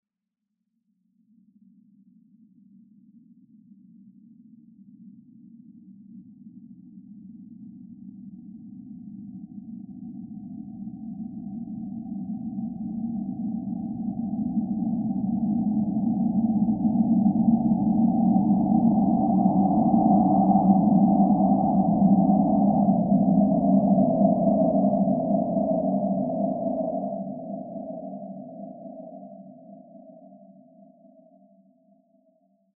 Резкие сирены, сигналы аварийной ситуации и другие звуковые эффекты помогут создать атмосферу напряжения для видео, подкастов или игровых проектов.
Шум от проплывающей рядом подводной лодки